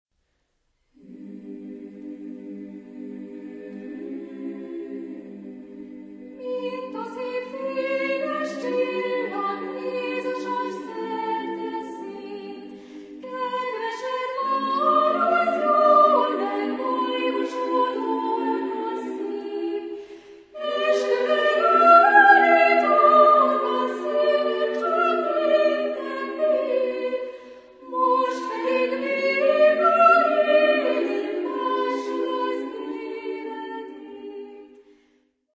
Genre-Stil-Form: Liebeslied
Charakter des Stückes: Andante moderato
Chorgattung: SSA  (3-stimmiger Frauenchor )
Solisten: Soprano (1)  (1 Solist(en))
Tonart(en): G-Dur